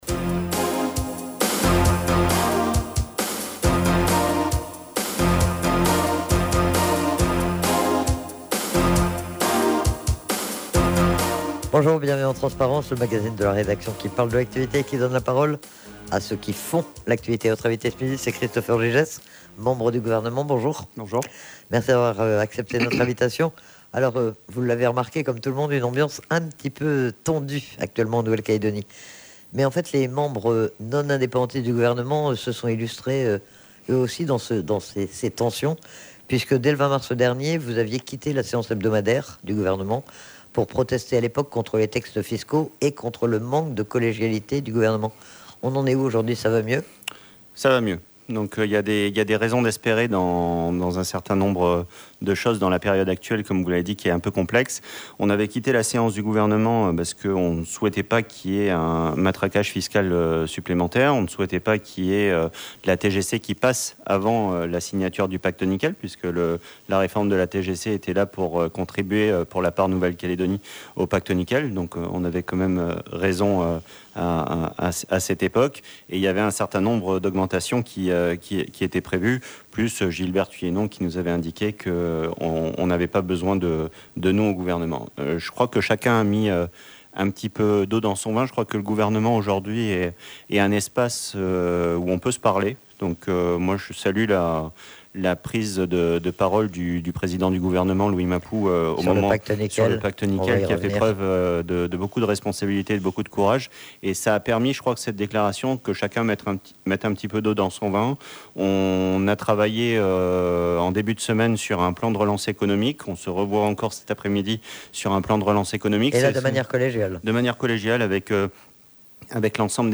La situation de la SLN et le pacte nickel. Ce sont quelques-uns des sujets sur lesquels a été interrogé Christopher Gygès, membre du gouvernement. Il est revenu plus largement, sur l'actualité politique de la Nouvelle-Calédonie et sur l'ambiance au sein de l'exécutif.